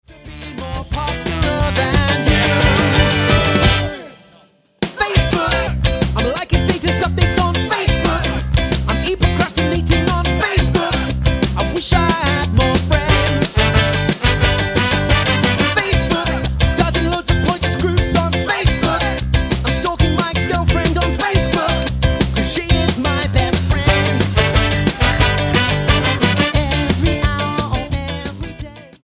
ska
Style: Rock